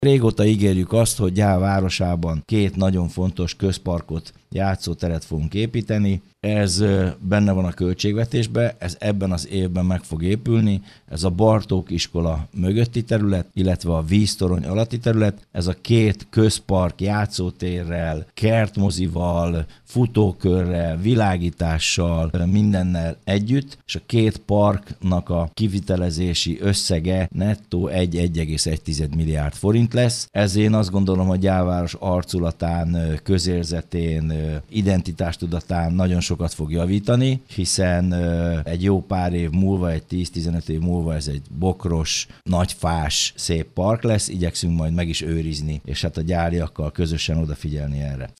Pápai Mihály polgármestert hallják.